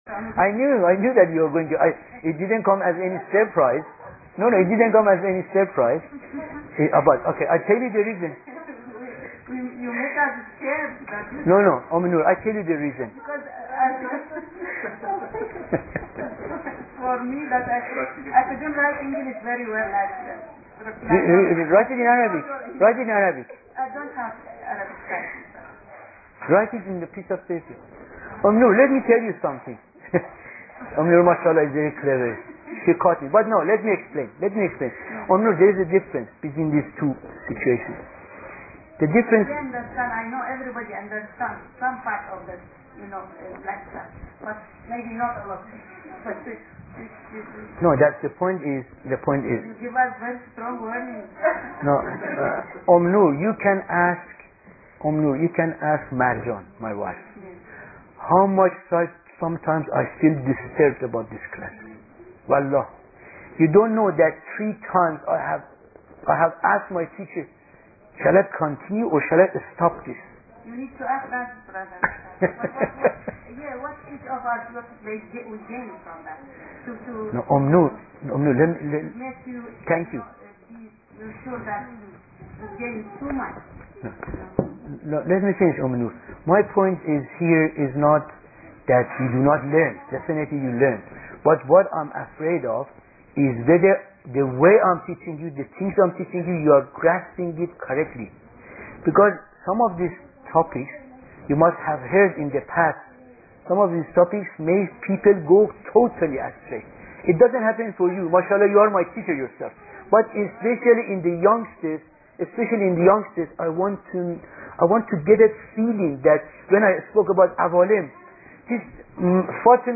Mysteries of Salaat (asrarol-salaat) : Audio Lecture Part 4